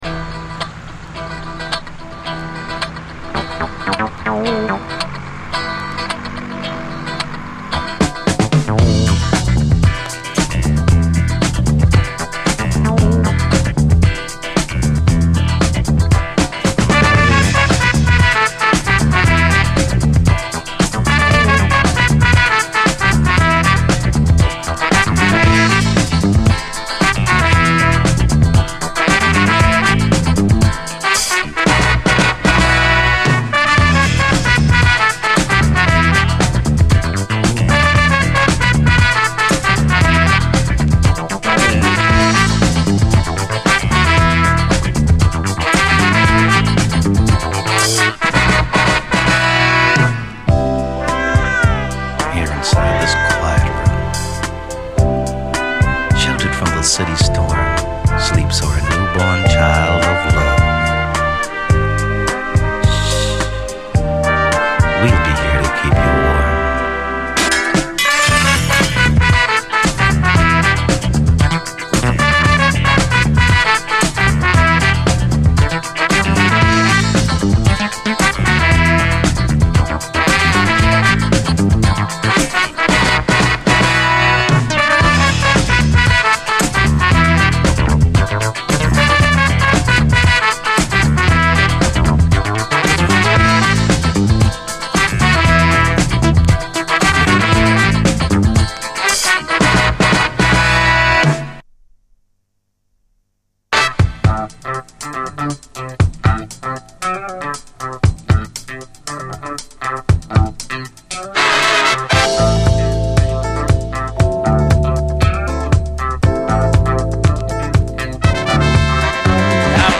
華麗なアレンジとズンドコ・ディスコ・ビートでこちらも最高。ディスコ・ブレイクもカッコいい！